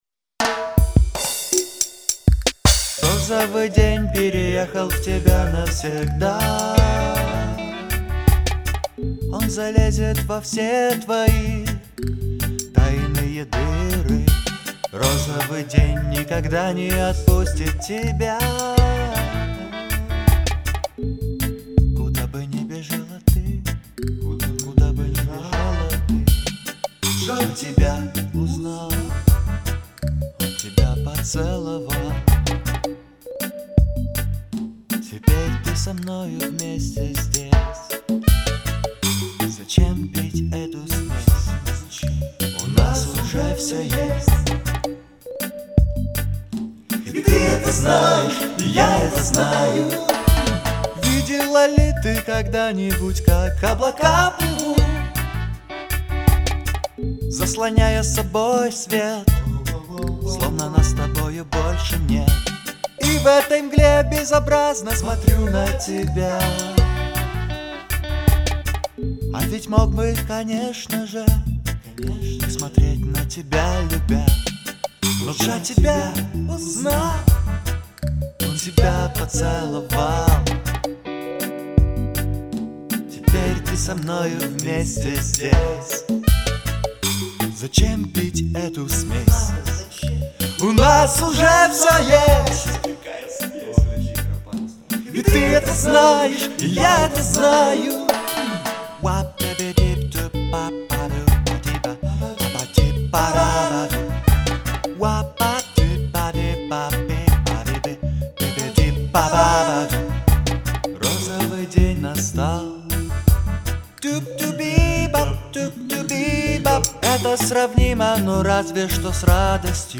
Альбом записан на домашней студии.